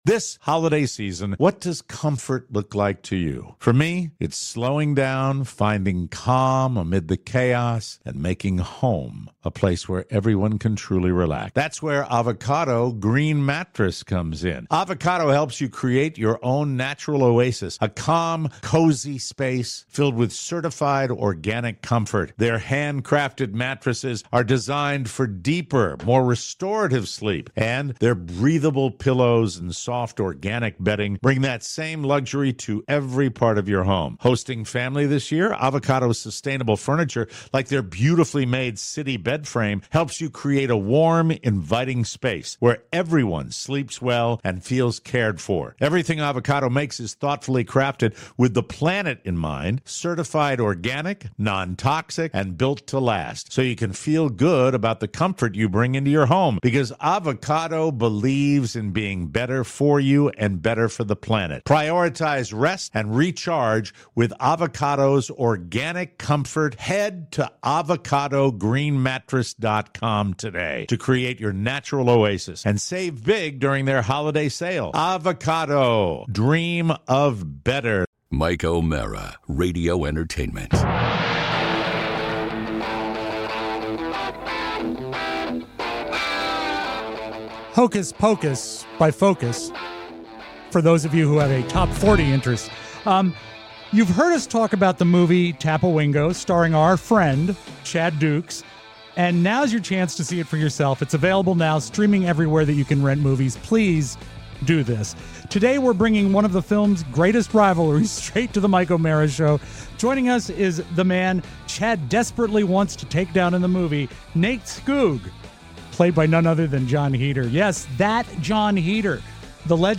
It’s a fun, lively conversation you don’t want to miss!